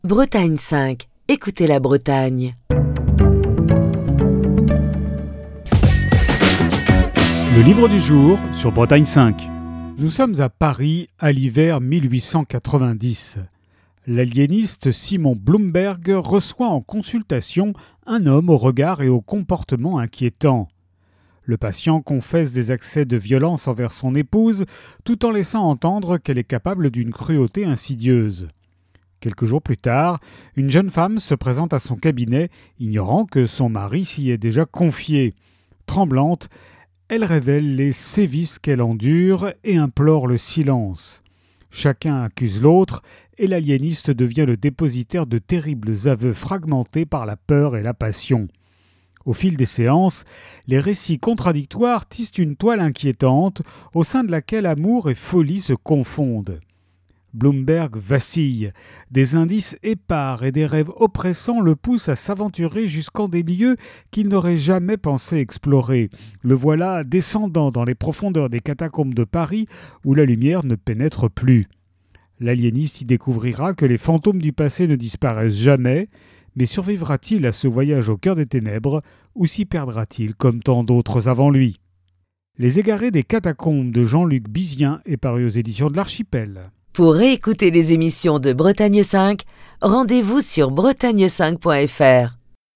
Chronique du 23 juin 2025.